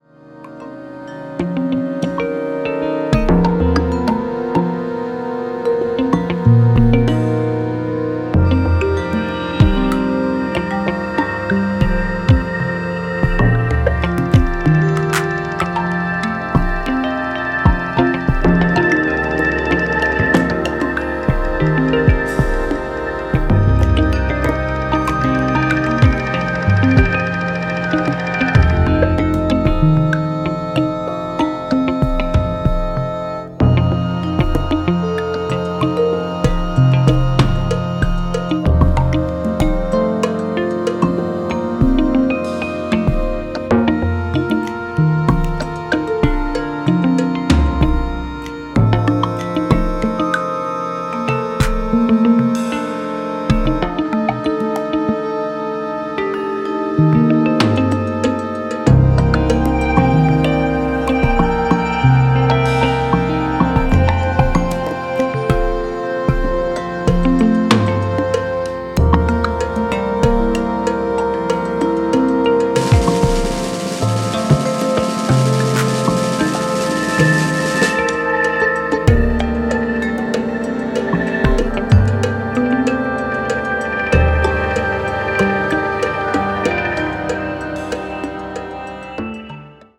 おおよそ95BPM程のゆったりとしたテンポで、パーカッションのポリリズミカルなフレーズ
ニューエイジ・オブ・ニューエイジな風情も感じられるミニマル・アンビエント音風景